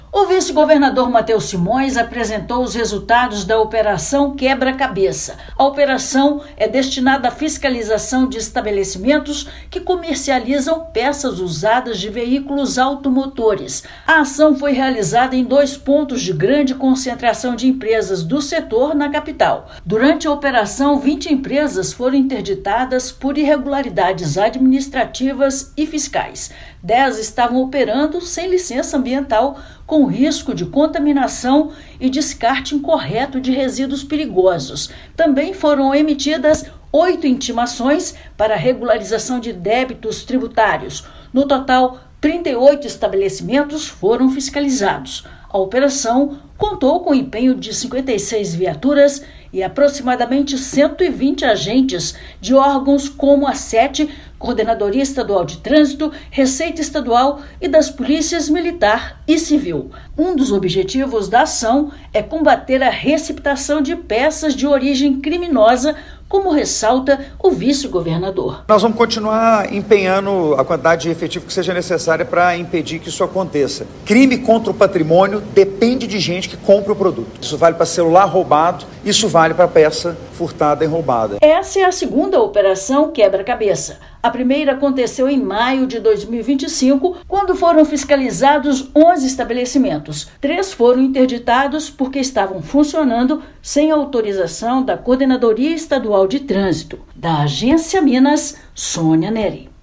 [RÁDIO] Governo de Minas apresenta balanço da operação de combate ao comércio clandestino de peças automotivas
Operação Quebra-Cabeça é uma ação integrada da Coordenadoria Estadual de Gestão de Trânsito, Receita Estadual, e das polícias Civil e Militar. Ouça matéria de rádio.